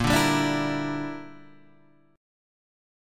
A#6b5 chord